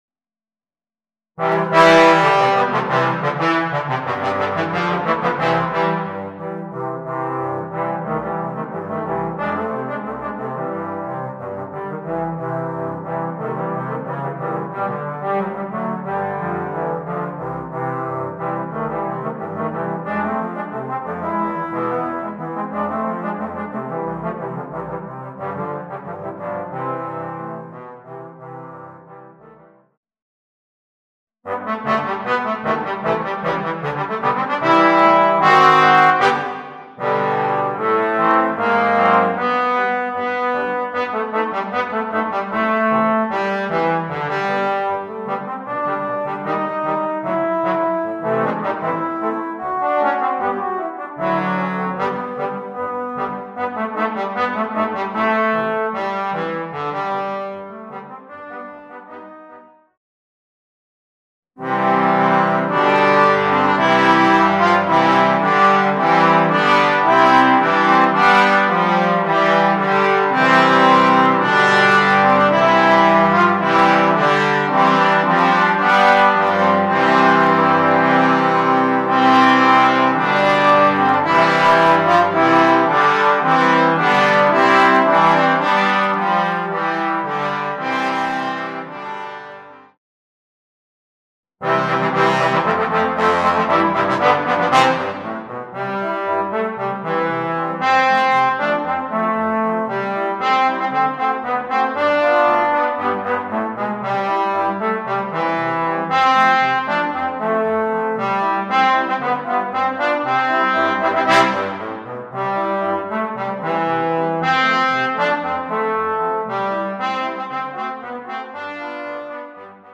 Voicing: 4 Trombones